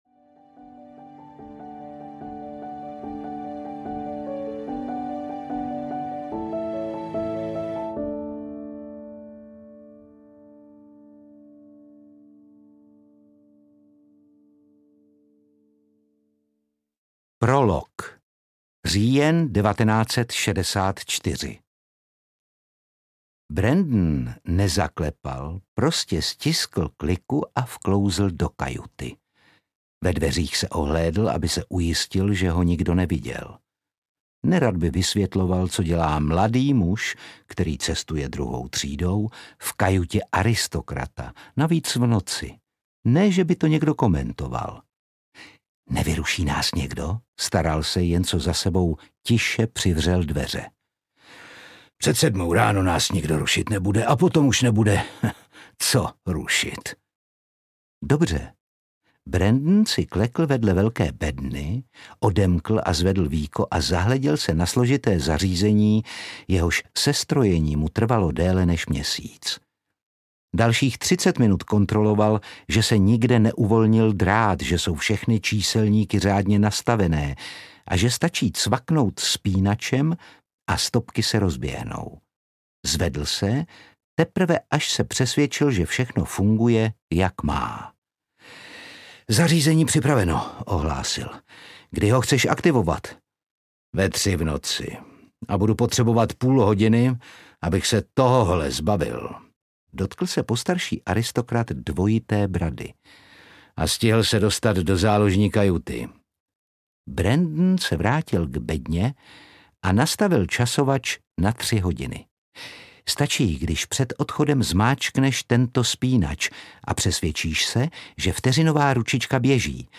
Mocnější než meč audiokniha
Ukázka z knihy
• InterpretOtakar Brousek ml.